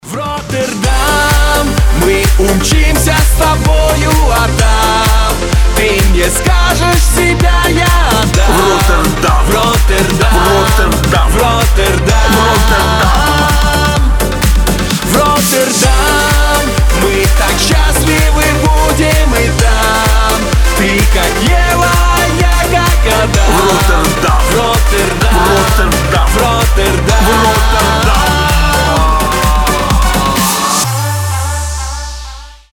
• Качество: 320, Stereo
громкие
зажигательные
веселые
попса